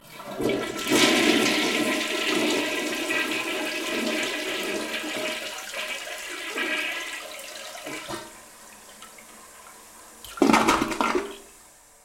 Toilet